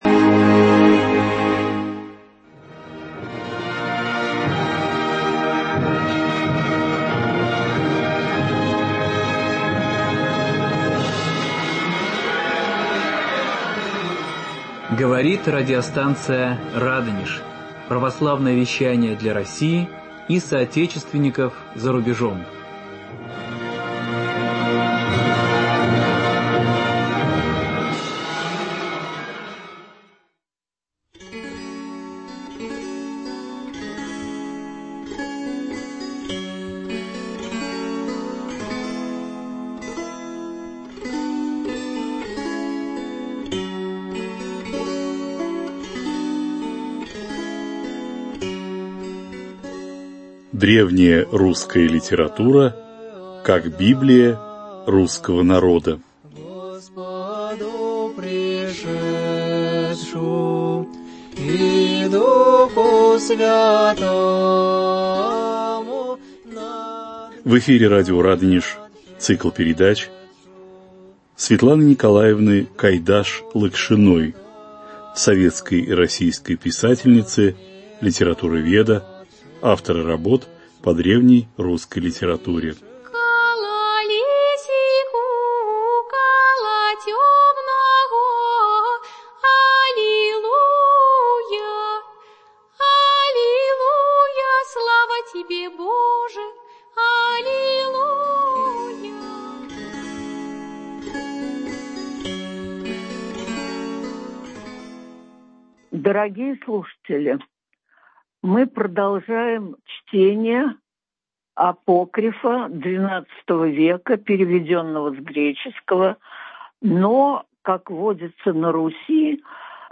Память их в род и род: беседа